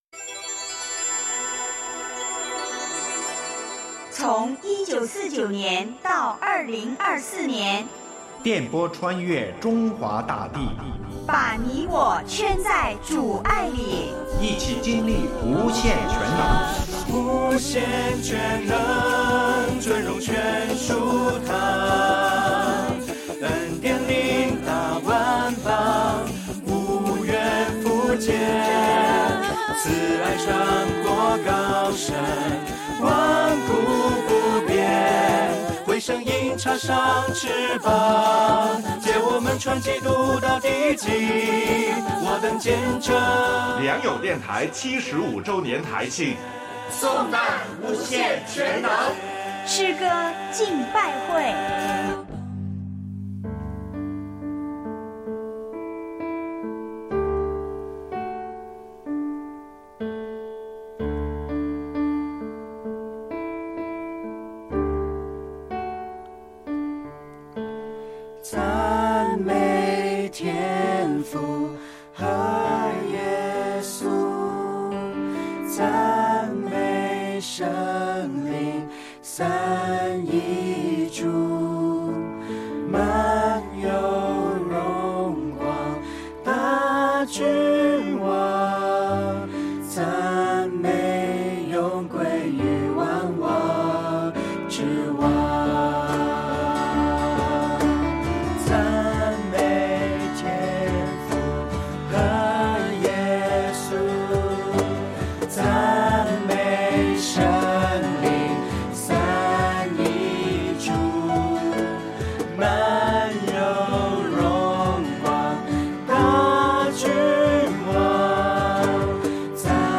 诗歌敬拜会